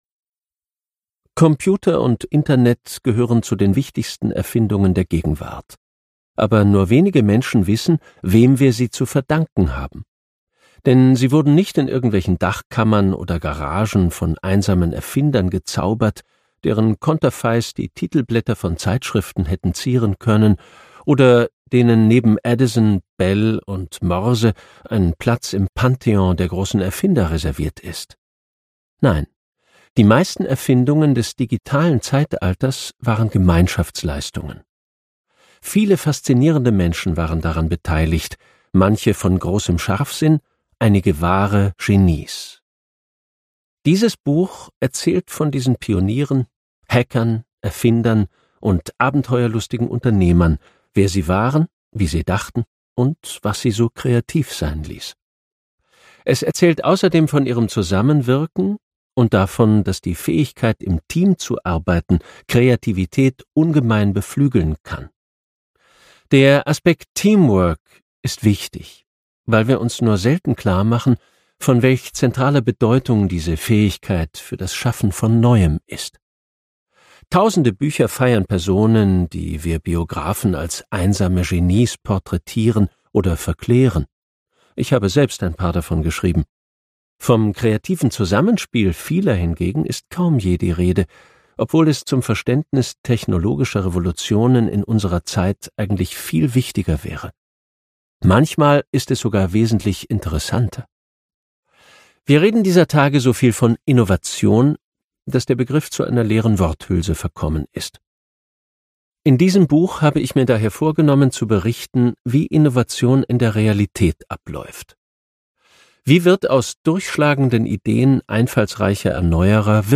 2018 | Gekürzte Lesung